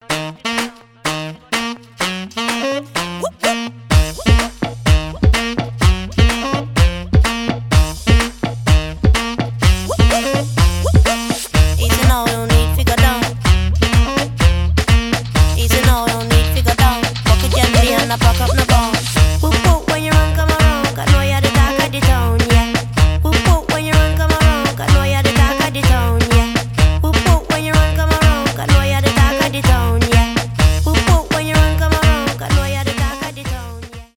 house , 2000-х , танцевальные , регги